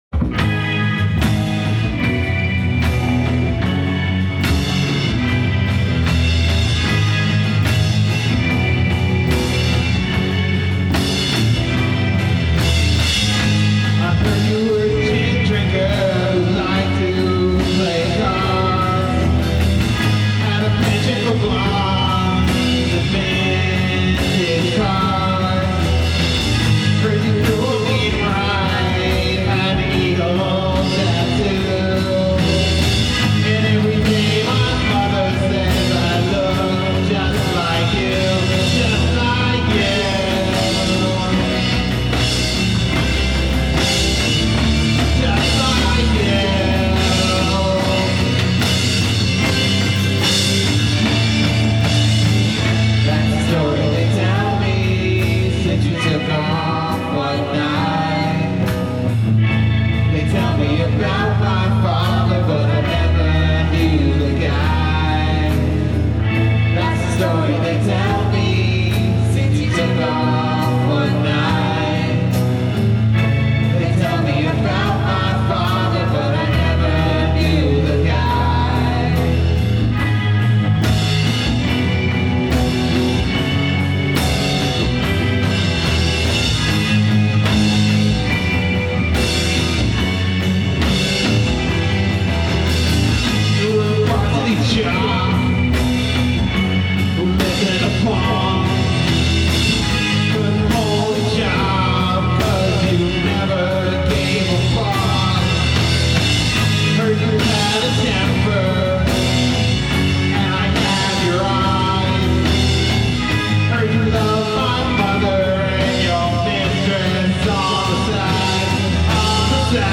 Ominous chord progression, which I like.